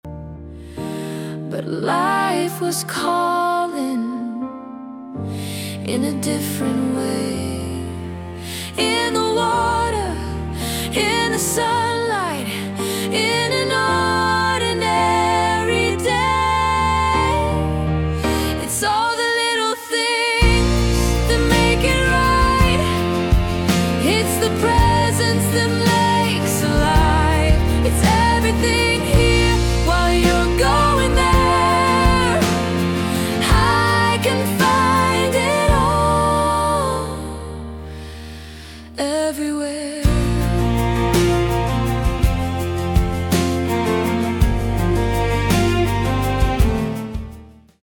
Kids’ Song
a tender, melodic song
• 🌈 Beautiful vocals and an inspiring message for all ages